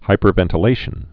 (hīpər-vĕntl-āshən)